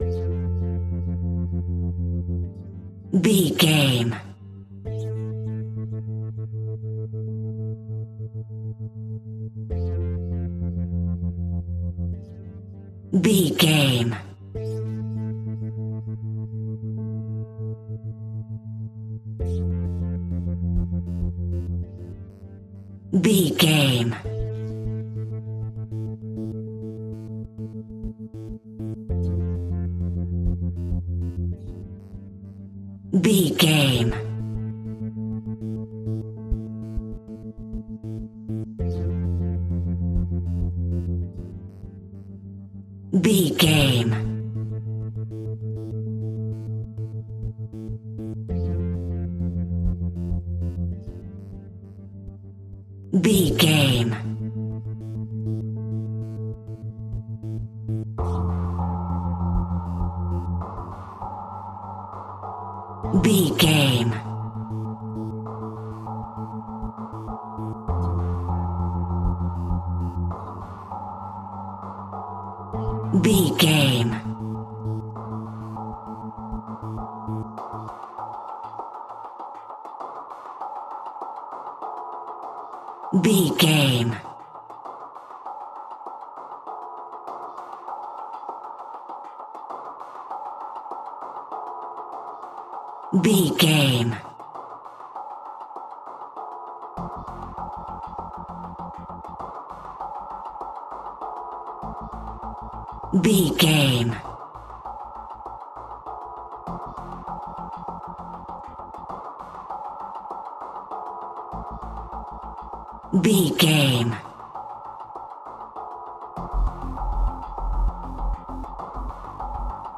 Tension and Suspense Music.
In-crescendo
Thriller
Aeolian/Minor
scary
ominous
dark
haunting
eerie
percussion
synthesizer
mysterious